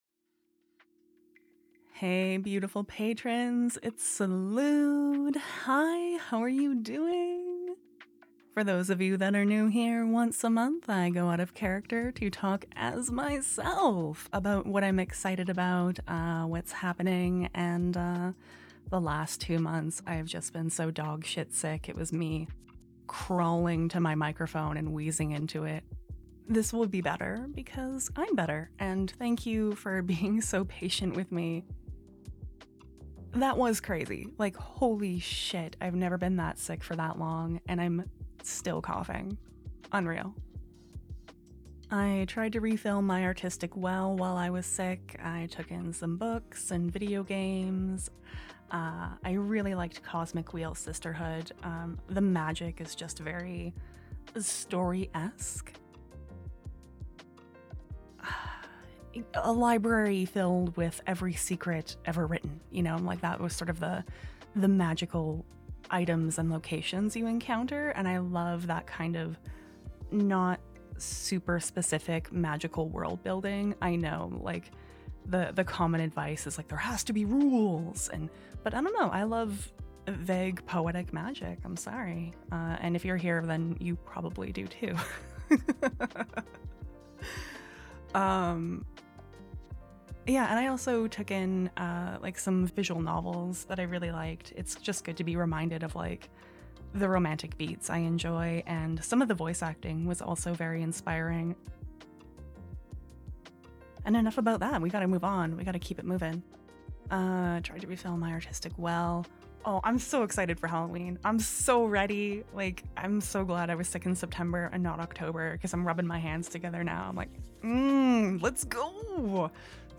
Hey everyone~! Feeling a lot better so I rambled a bit about refilling my artistic well and stories this month~! There's some awkward cuts because I'm still coughing~Some things I forgot to talk about:-Patreon changed some things again, the notifications tab for me is pretty rough.